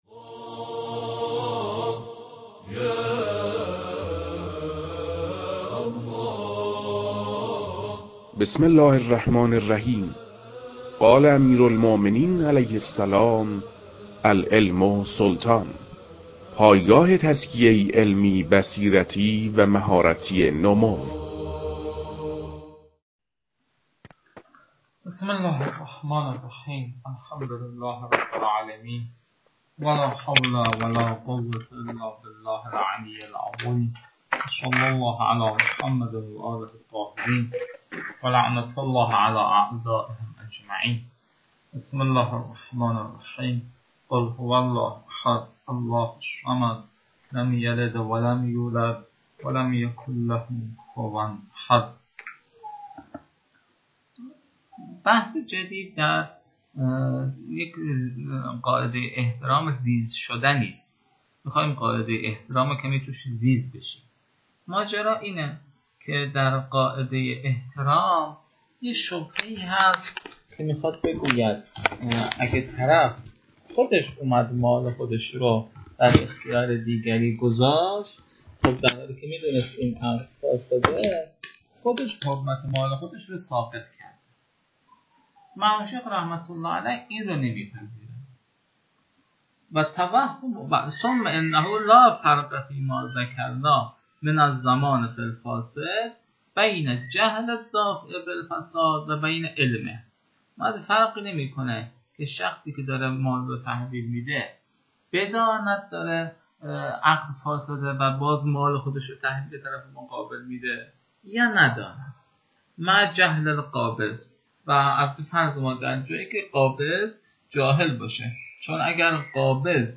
در این بخش، فایل های مربوط به تدریس مباحث تنبیهات معاطات از كتاب المكاسب متعلق به شیخ اعظم انصاری رحمه الله